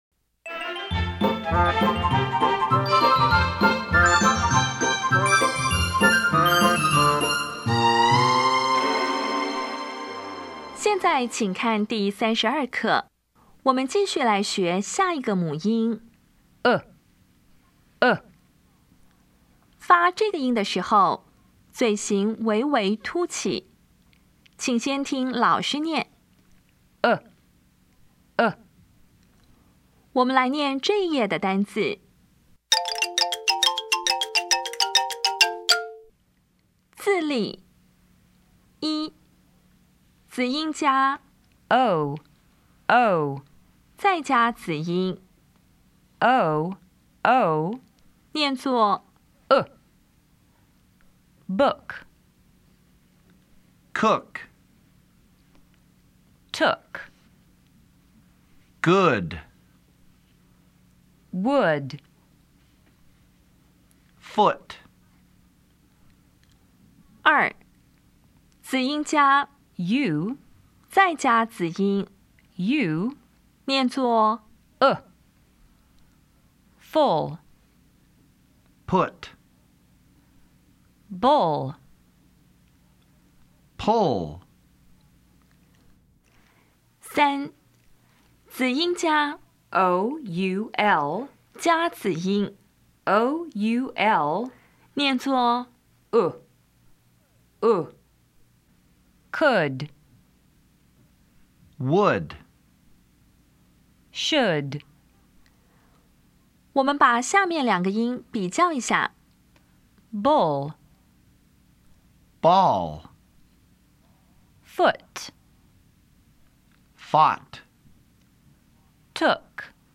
[ʊ]
音标讲解第三十二课
比较[ʊ] 与 [ɔ]